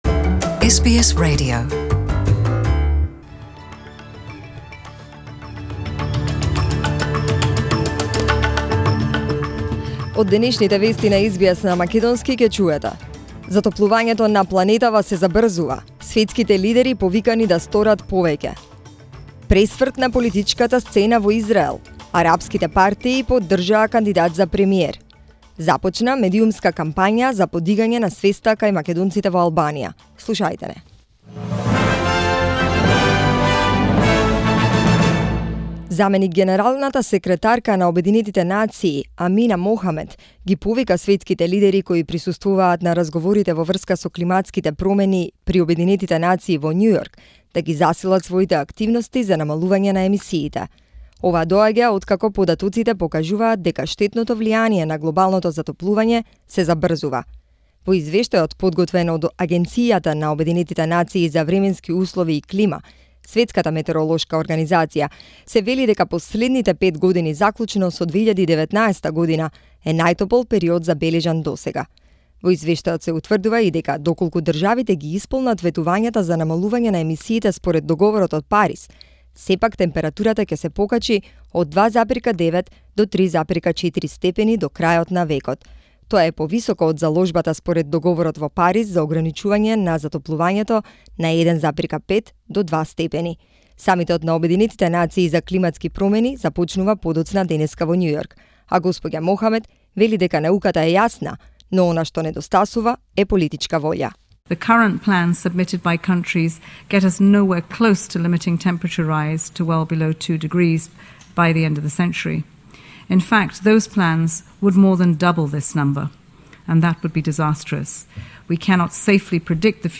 SBS Radio news in Macedonian, 23th September 2019